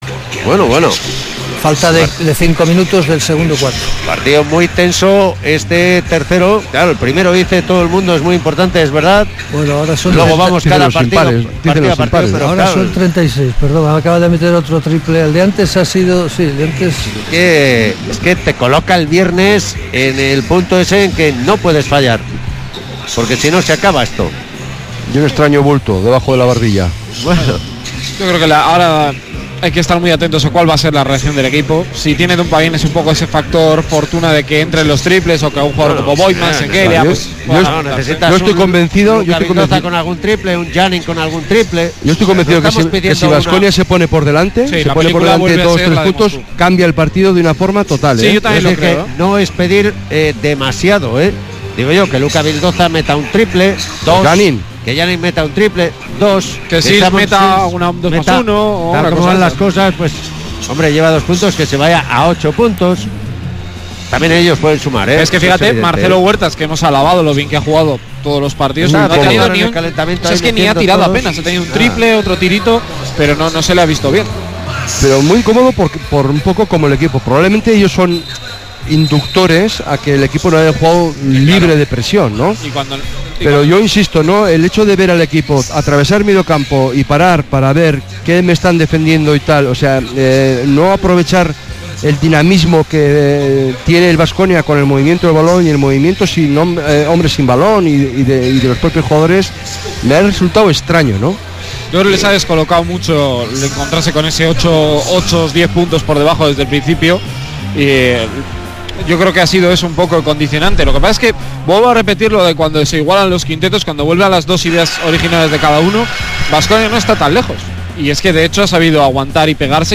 Kirolbet Baskonia-CSKA Moscu partido 3 play off euroleague 2018-19 retransmisión Radio Vitoria (segunda parte)